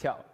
jump.opus